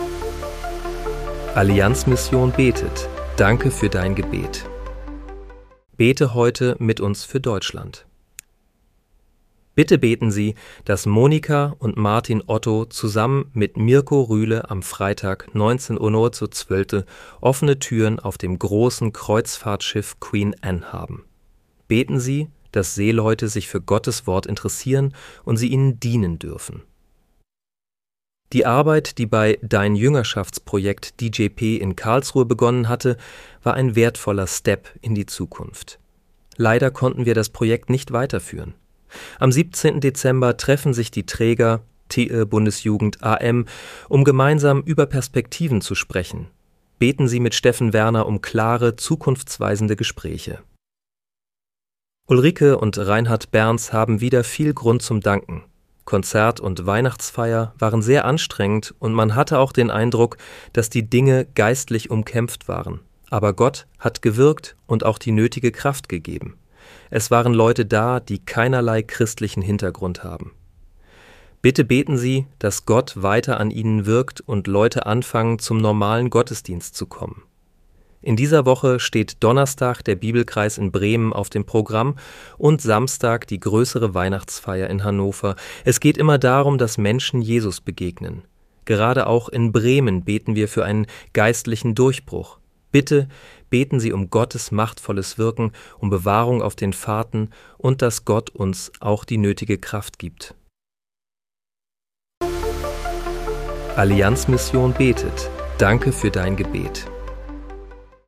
Bete am 18. Dezember 2025 mit uns für Deutschland. (KI-generiert